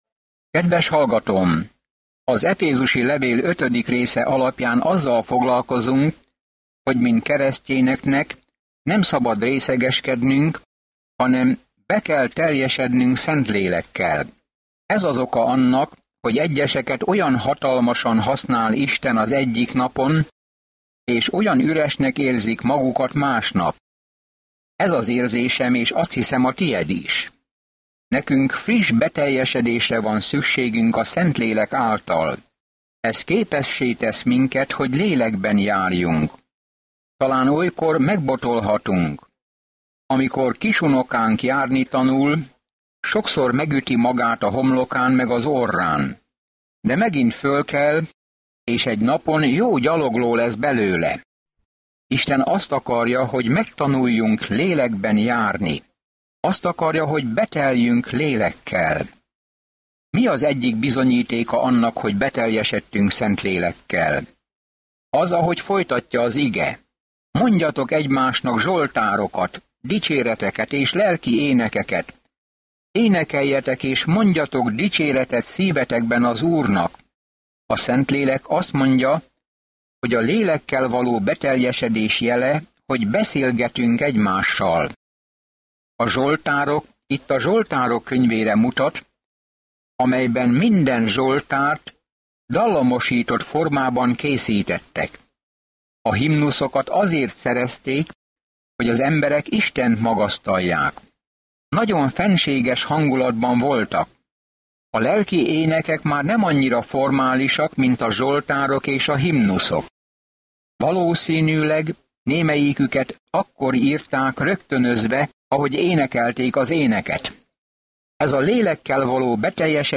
Szentírás Efezus 5:20-24 Nap 20 Olvasóterv elkezdése Nap 22 A tervről Az efézusiakhoz írt levél elmagyarázza, hogyan kell Isten kegyelmében, békéjében és szeretetében járni, a csodálatos magasságokból, hogy mit akar Isten gyermekei számára. Napi utazás az efézusi levélben, miközben hallgatod a hangos tanulmányt, és olvasol válogatott verseket Isten szavából.